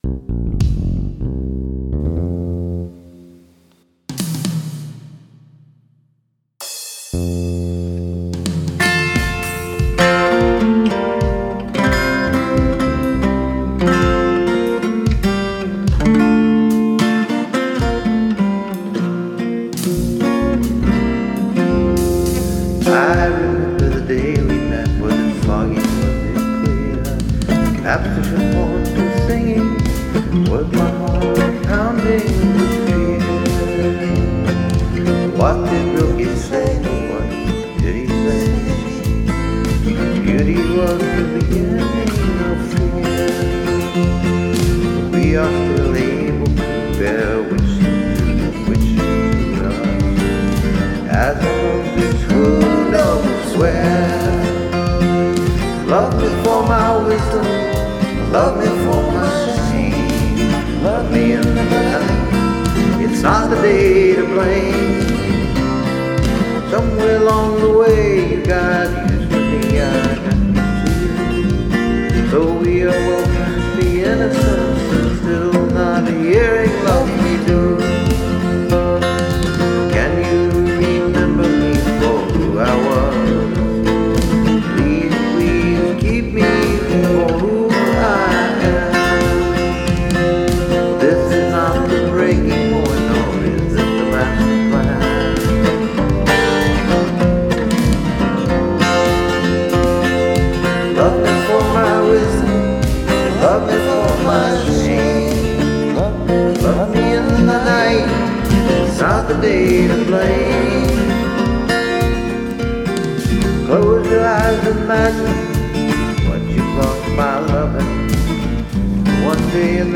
Anyway, here’s my draft of Love Me, which was recorded on an iPad.
THe acoustic guitar part was recorded after that and then a guide vocal, so I could visual where to come in.